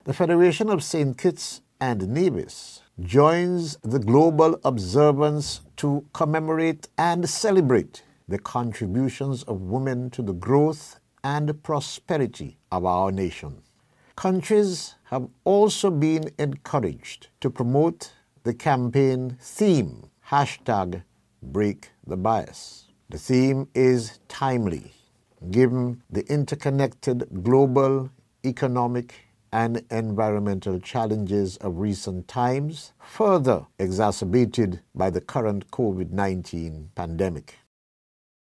Federal Minister of Gender Affairs, the Hon. Eugene Hamilton made these remarks in light of the celebration: